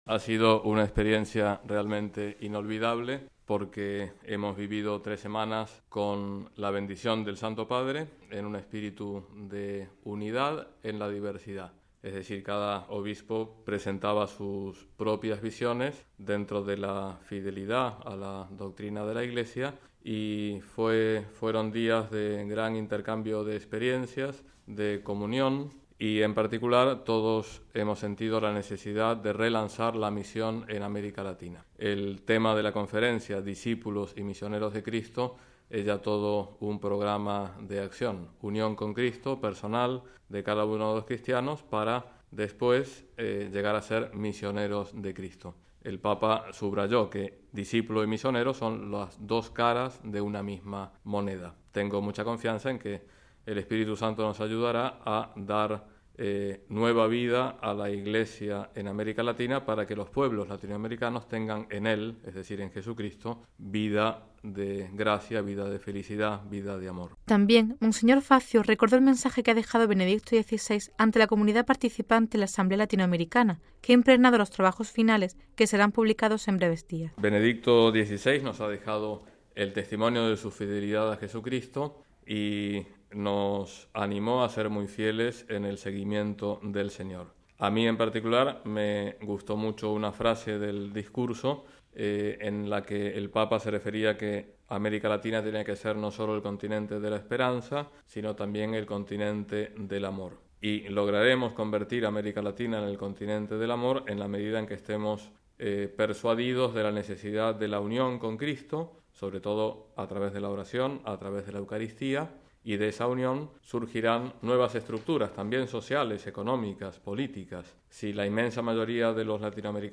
Les ofrecemos a continuación unas declaraciones